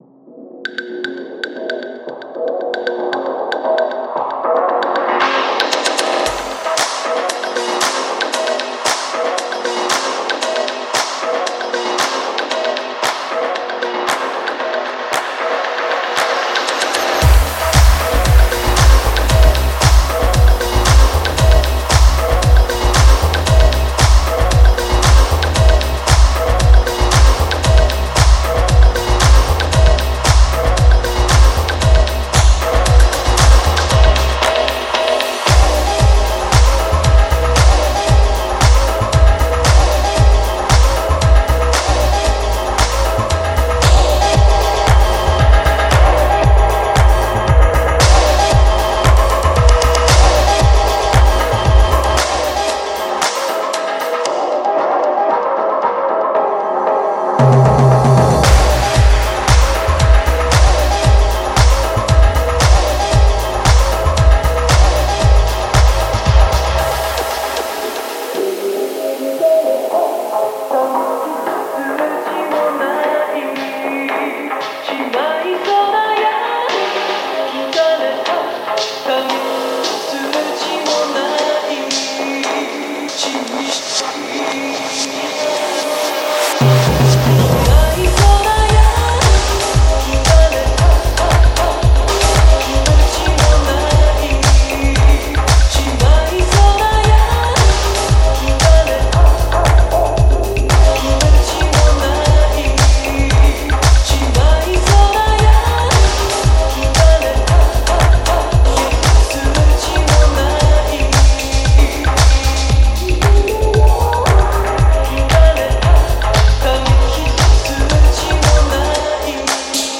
Directory: /music/Future Funk/